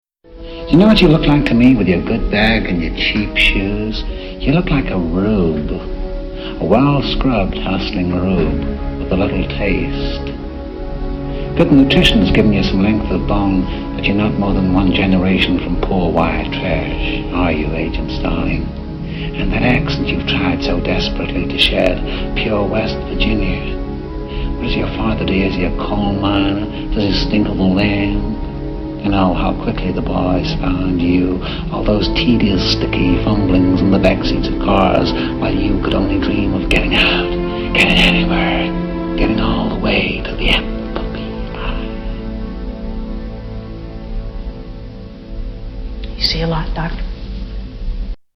Tags: Greatest Movie Monologues Best Movie Monologues Movie Monologues Monologues Movie Monologue